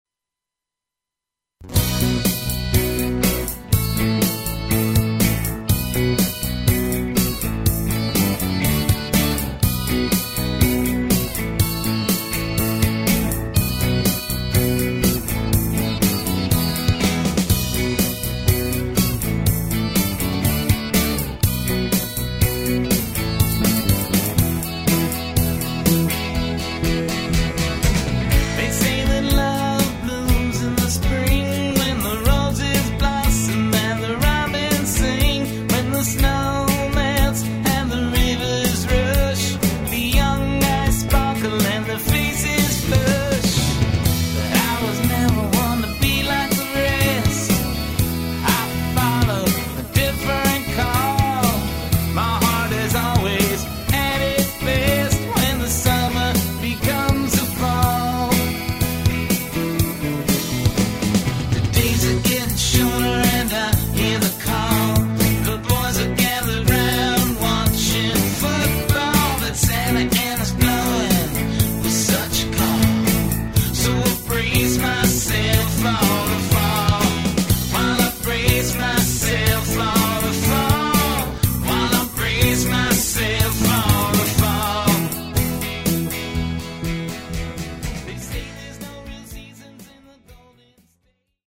vocals
drum programming
Neo-Americana music